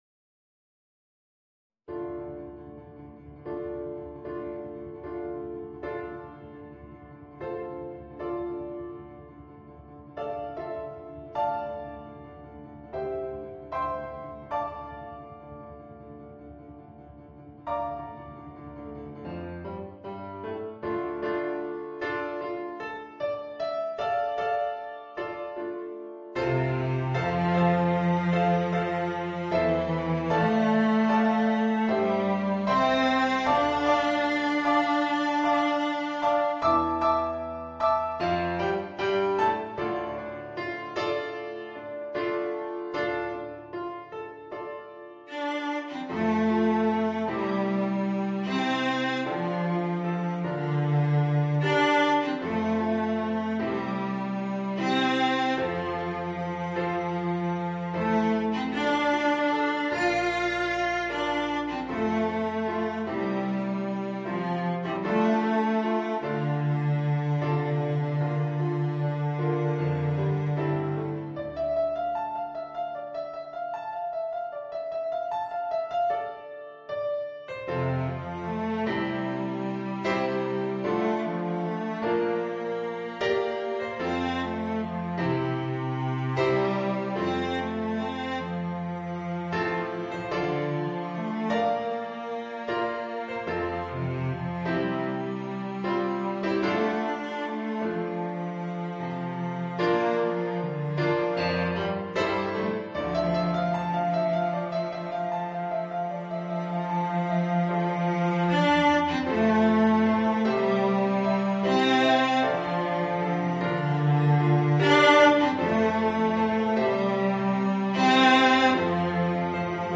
Voicing: Violoncello and Piano